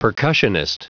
Prononciation du mot percussionist en anglais (fichier audio)
Prononciation du mot : percussionist